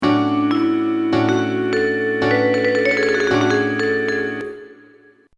– not to mention more complex quasi-irrational sequences: